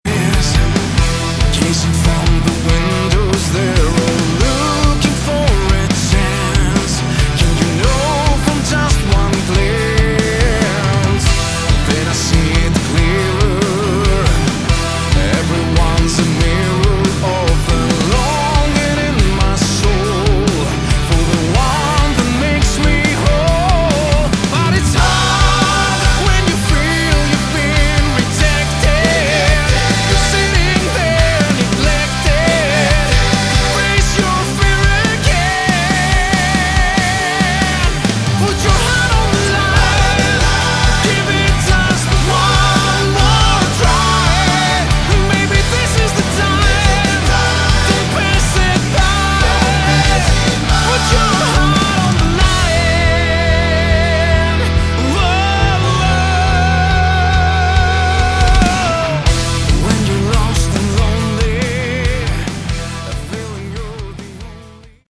Category: Melodic Rock
lead vocals
rhythm guitar, backing Choirs, Programming
bass, Harmony vocals
guitar solo
keyboard solo
piano
wawah guitar
Superb piece of British AOR